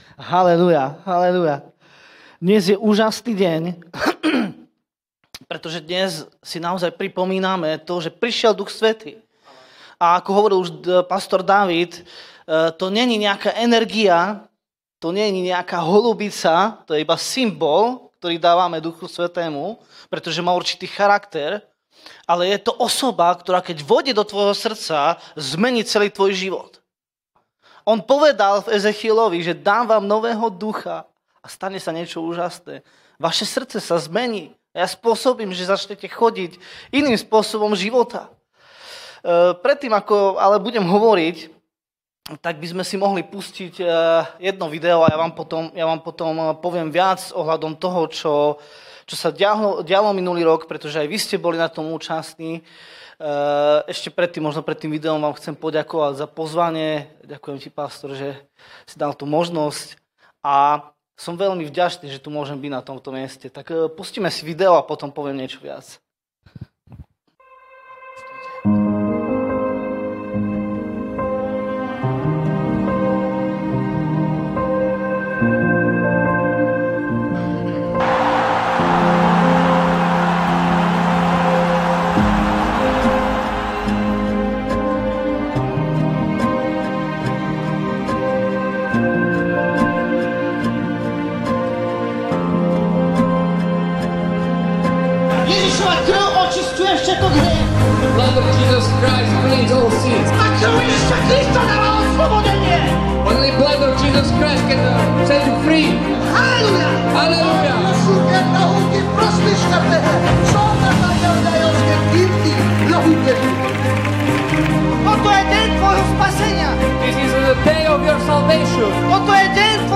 Pro váš užitek zveřejňujeme výběr z nahrávek biblických kázání Apoštolské církve ve Vyškově.
Kázání